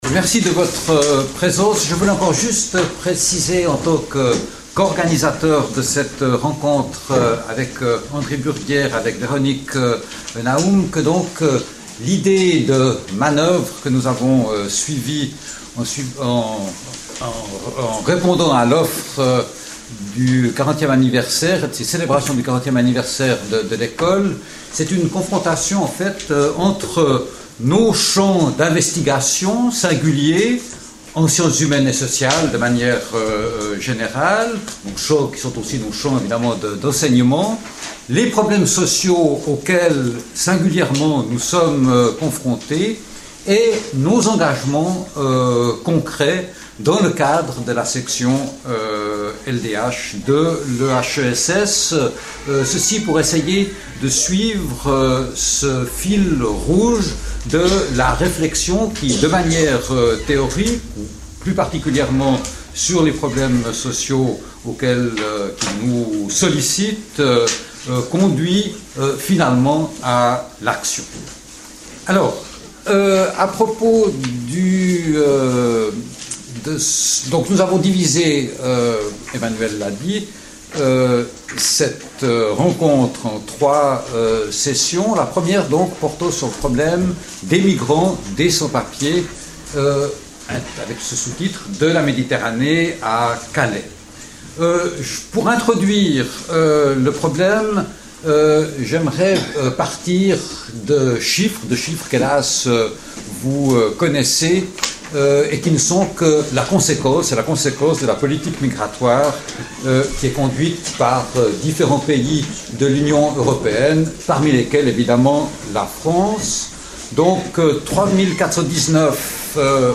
Table-ronde